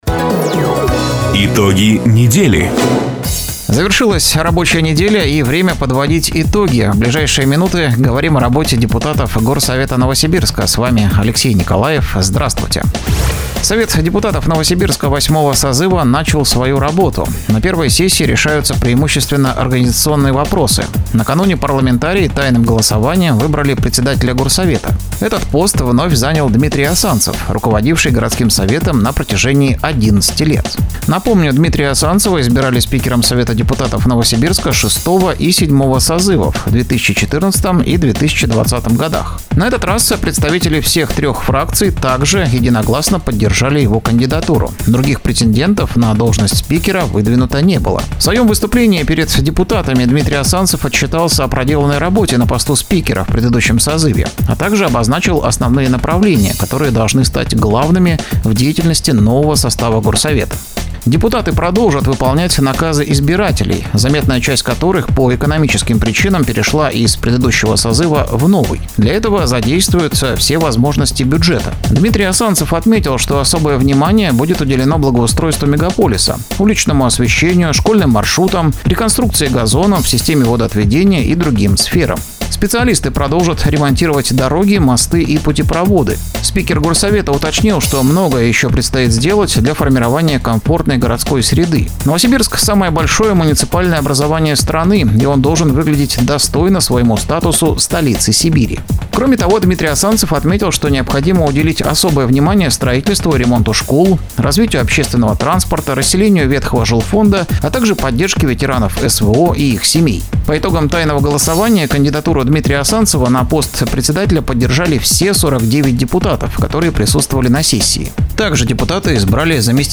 Запись программы "Итоги недели", транслированной радио "Дача" 27 сентября 2025 года